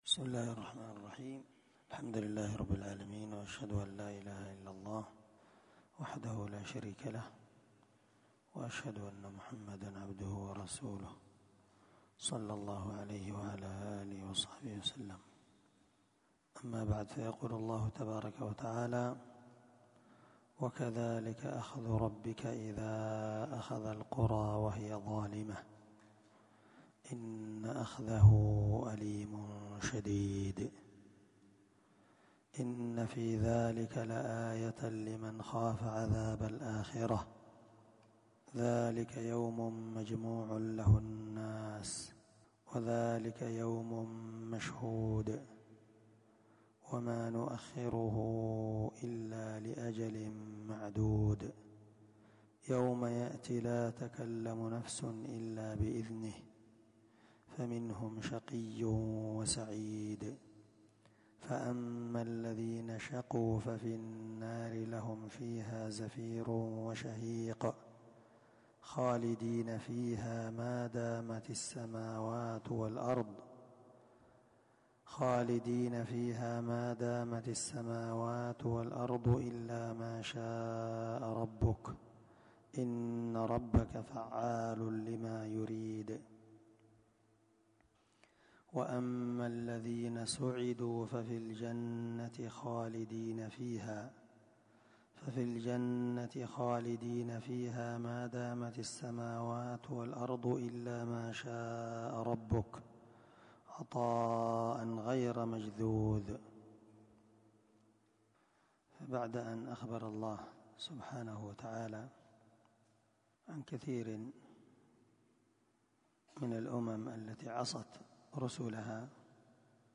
645الدرس 26تفسير آية ( 102- 108) من سورة هود من تفسير القرآن الكريم مع قراءة لتفسير السعدي